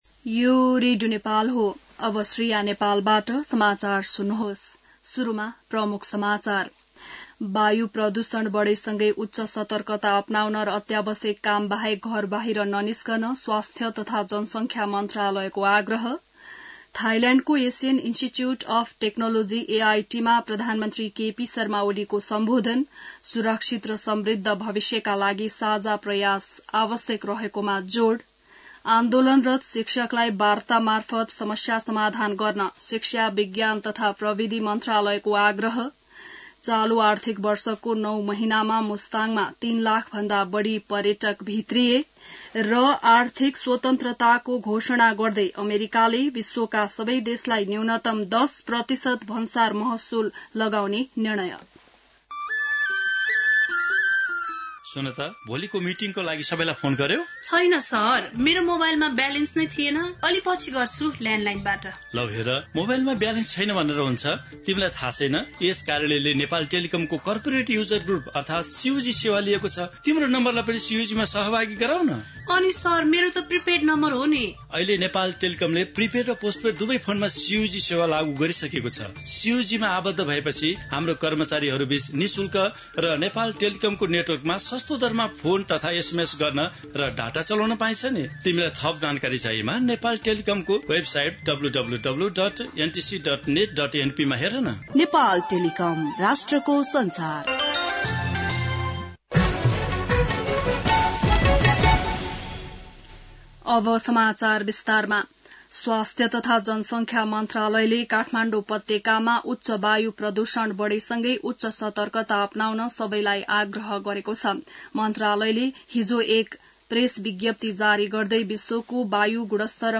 बिहान ७ बजेको नेपाली समाचार : २२ चैत , २०८१